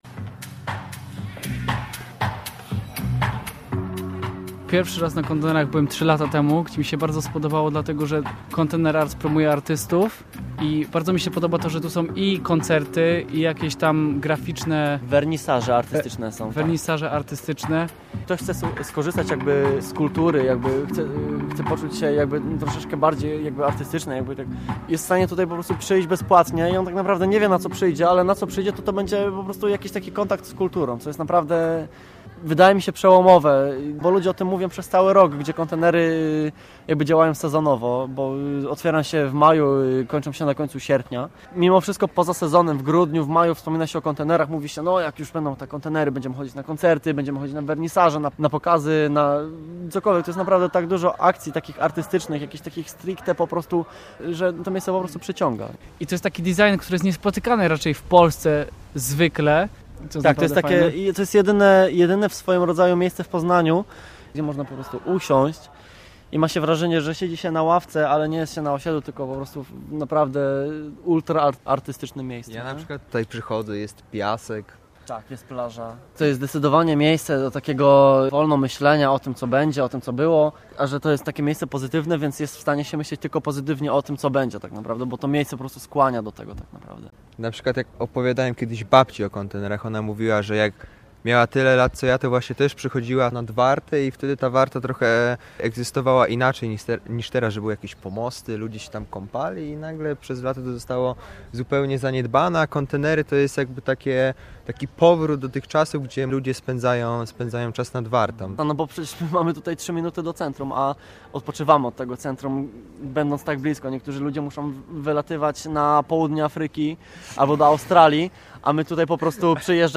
Bliżej Warty - reportaż